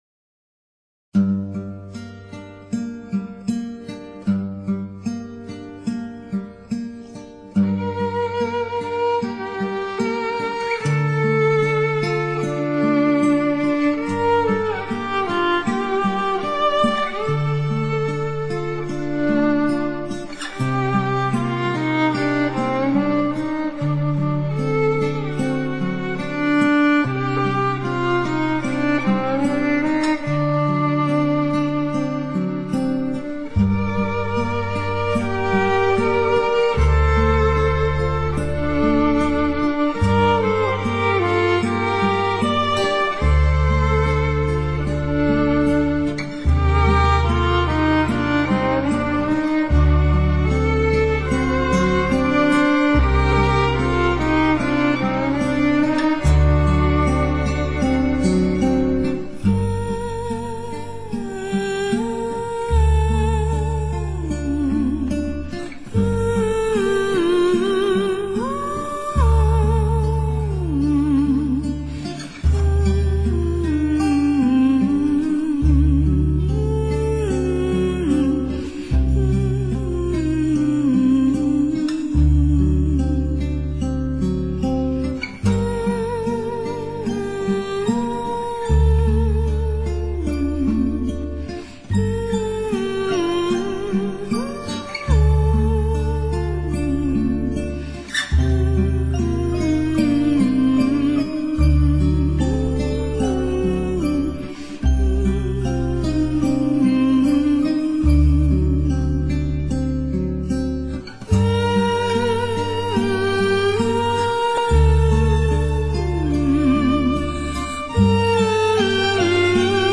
[11/24/2007]一曲清旋，幽静而恬婉……